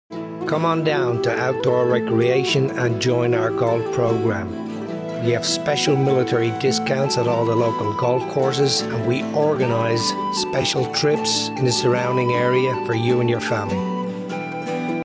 a brief Radio commercial for Outdoor recreation showcasing some of the services they offer.